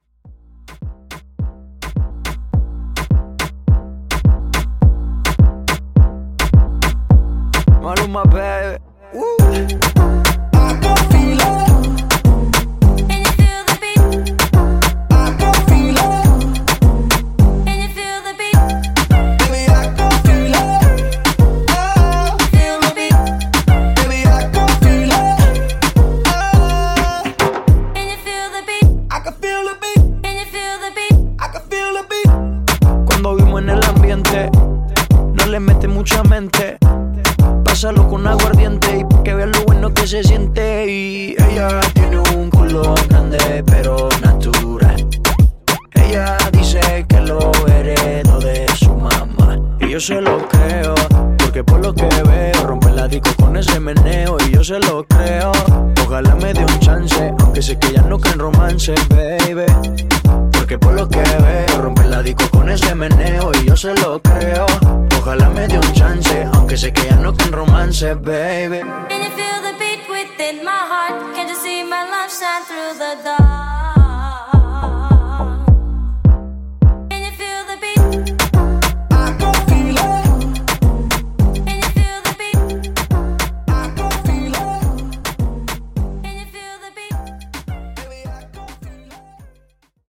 DanceHall Edit)Date Added